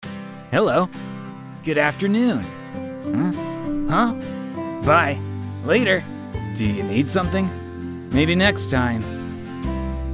Eric NPC Voice Pack | Voices Sound FX | Unity Asset Store